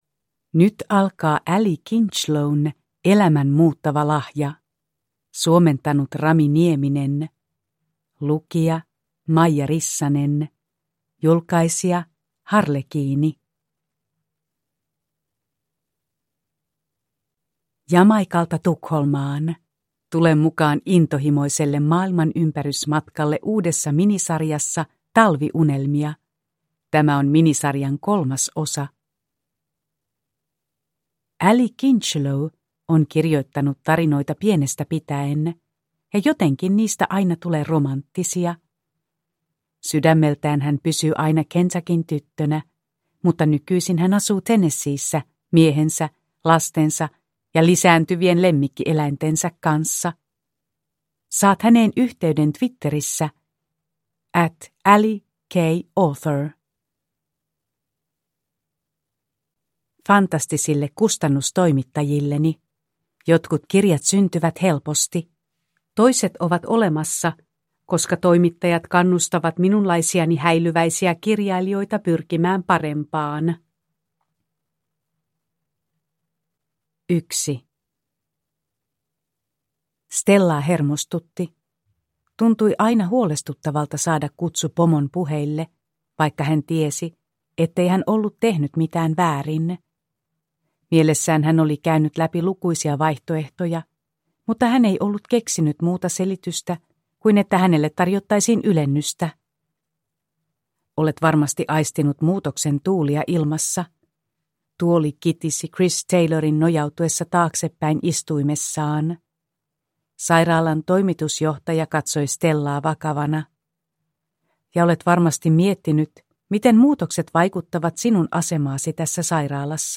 Elämän muuttava lahja (ljudbok) av Allie Kincheloe